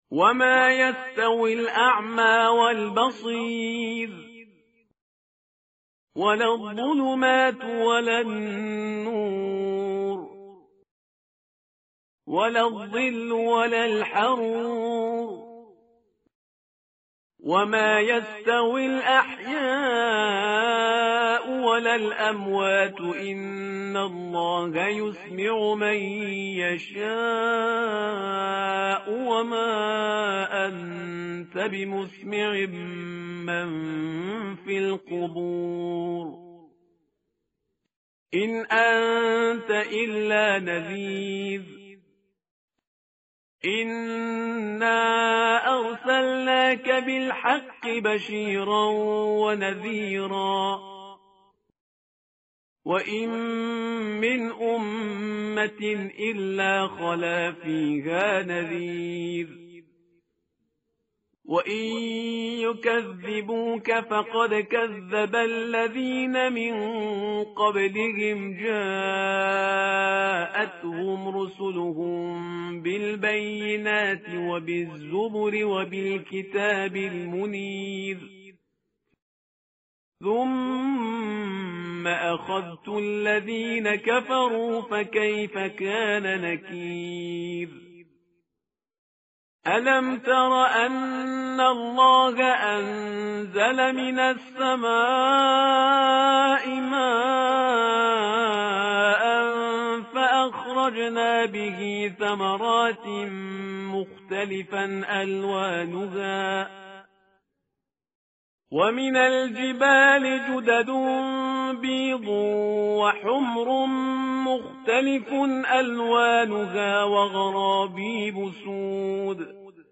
tartil_parhizgar_page_437.mp3